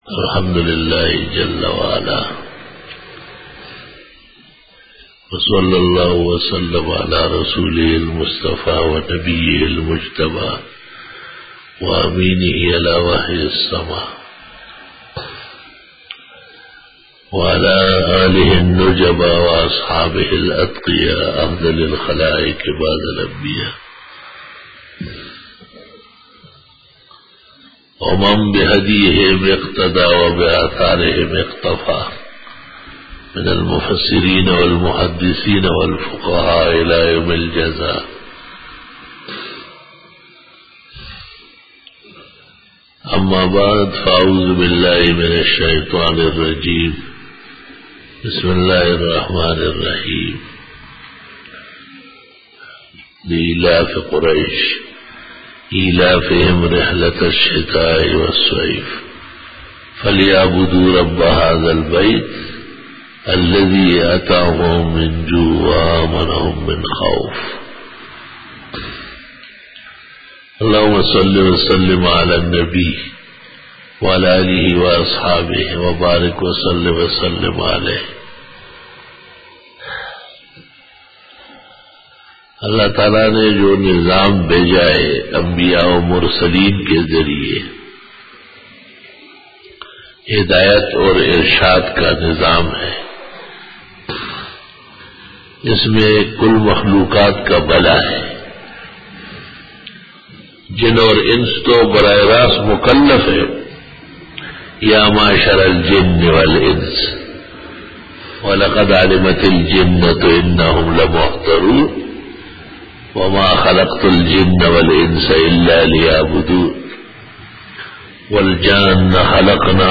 05_BAYAN E JUMA TUL MUBARAK 31-JANUARY-2014
بیان جمعۃ المبارک 31 جنوری 2014